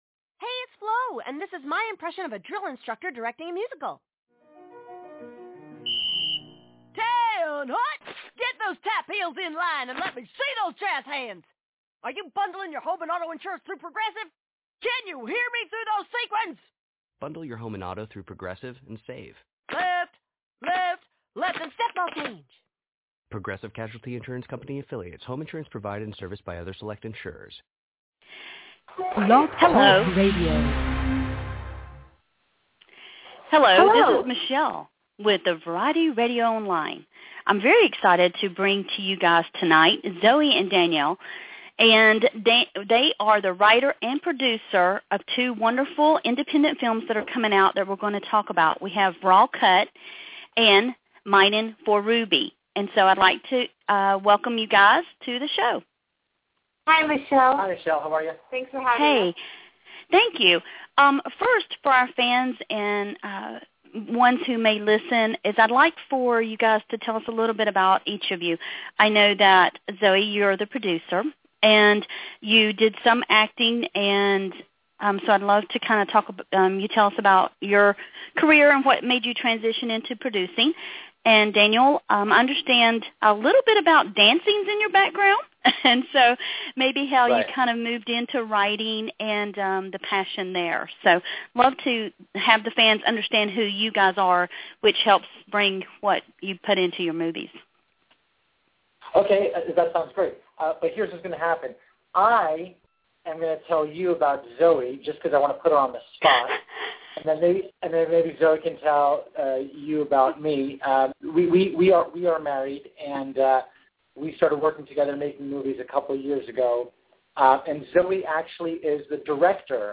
"Raw Cut" - Interview